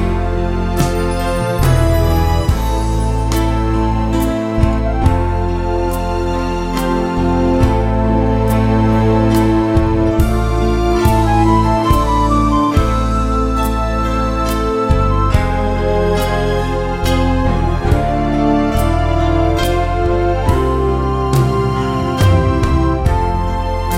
Crooners